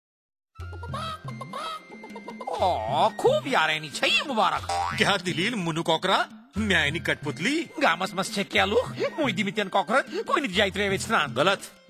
This is fourth in the series of five Radio PSA and address backyard farmers and their families. It also uses a performer and a rooster puppet as a creative medium to alert families to poultry diseases and instill safe poultry behaviours.
Radio PSA